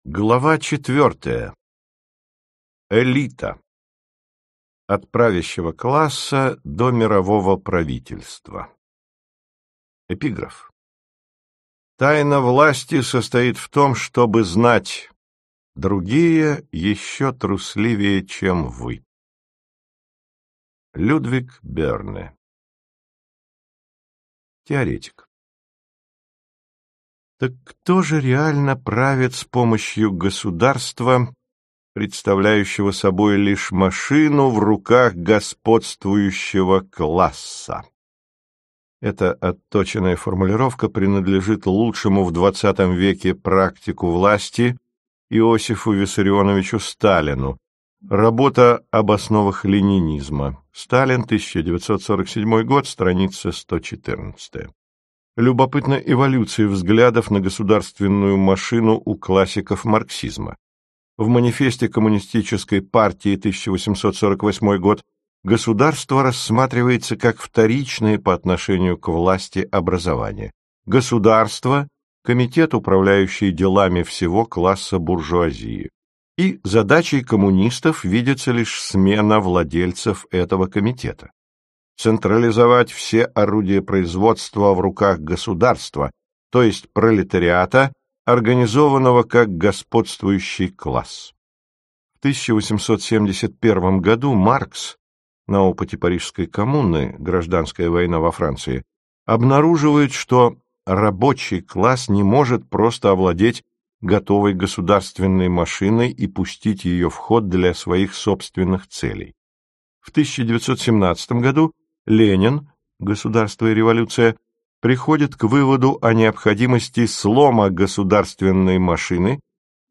Аудиокнига Лестница в небо. Диалоги о власти, карьере и мировой элите. Часть 4 | Библиотека аудиокниг